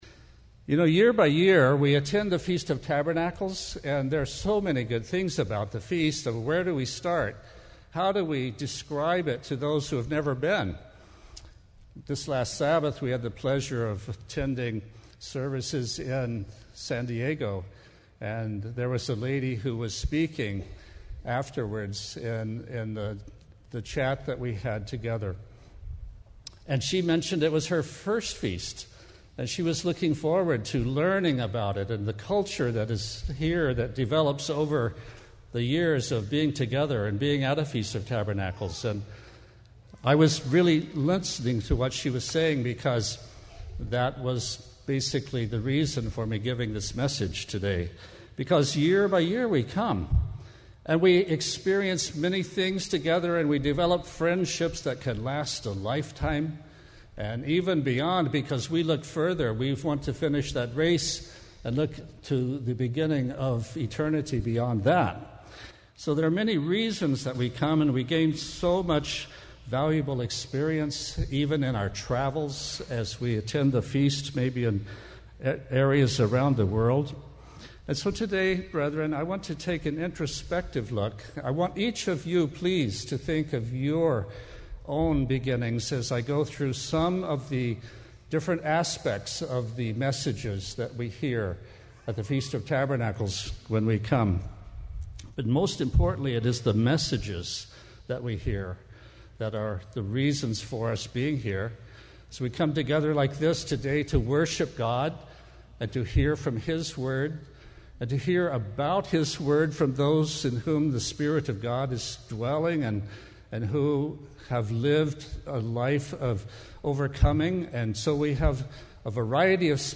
This sermon was given at the Oceanside, California 2016 Feast site.